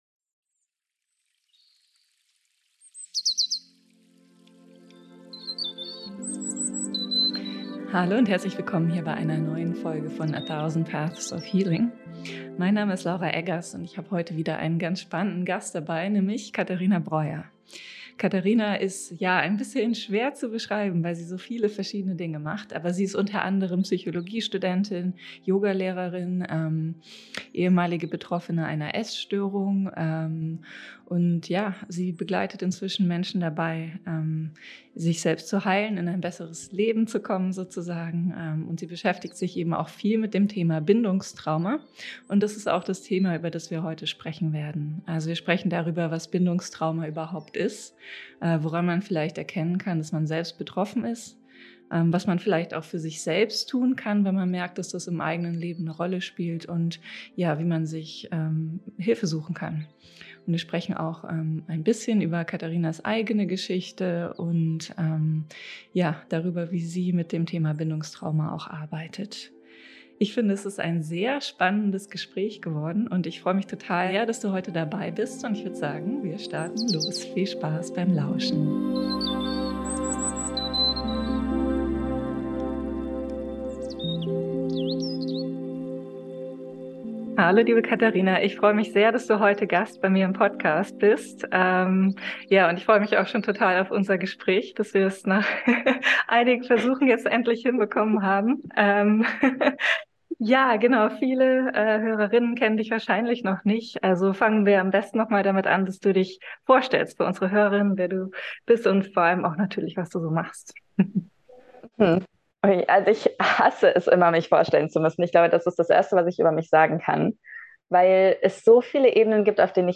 Wie das Wissen über Bindungstrauma unser Leben verändern kann - Interview